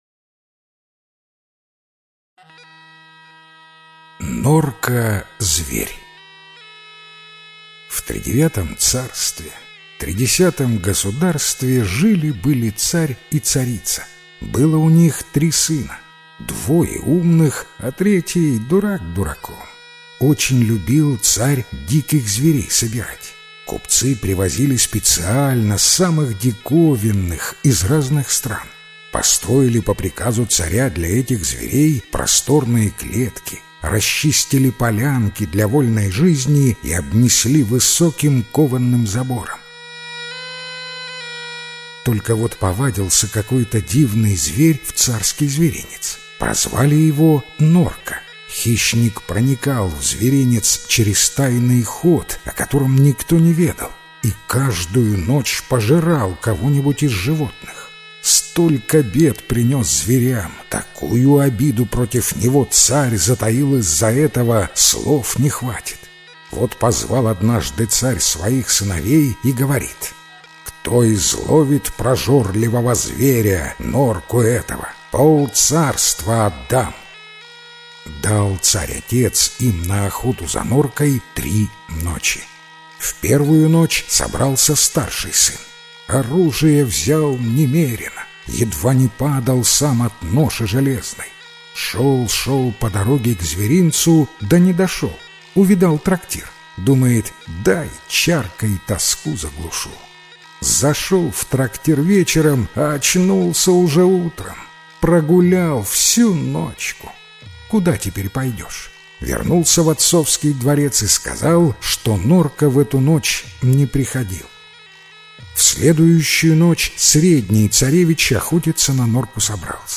Норка-зверь - белорусская аудиосказка - слушать онлайн